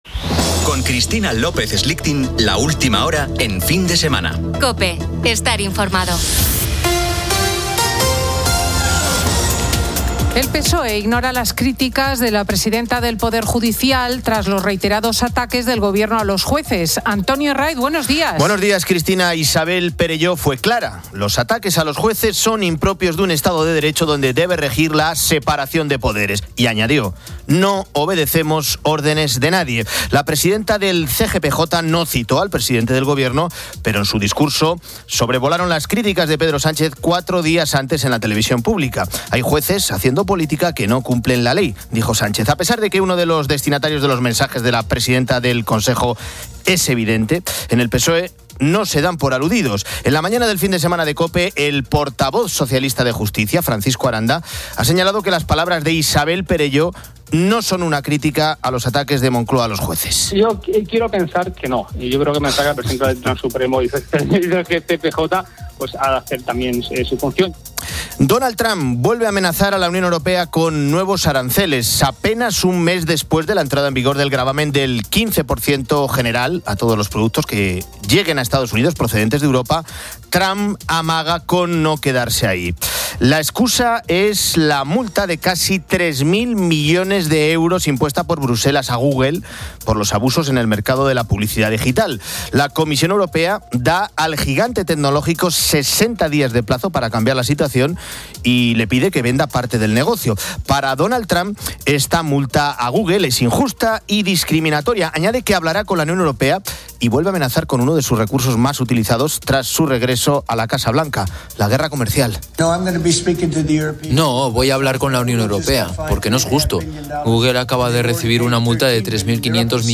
Fin de Semana 10:00H | 06 SEP 2025 | Fin de Semana Editorial de Cristina López Schlichting, que analiza la actualidad de la mano del compañero periodista Antonio Jiménez.